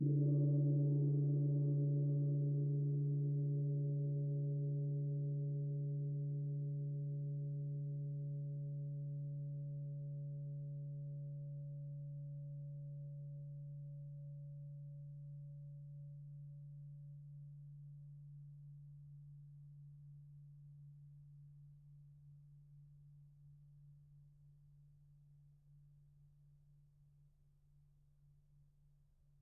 gongHit_p.wav